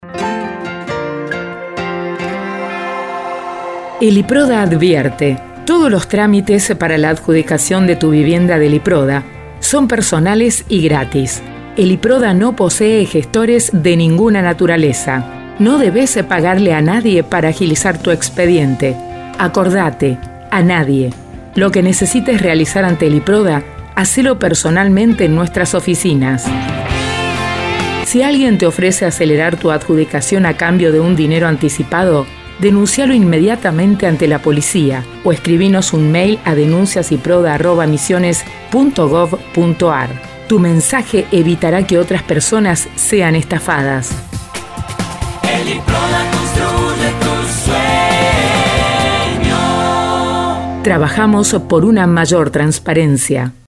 Este es el spot del IPRODHA donde dice claramente que no tienen gestores, ya que están cerca de una entrega de viviendas, y han recibido varias denuncias de “supuestos gestores” del Instituto (que por supuesto son personas ajenas al organismo) y desafortunadamente han estafado a algunos inscriptos prometiéndoles viviendas.